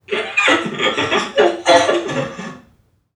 NPC_Creatures_Vocalisations_Robothead [59].wav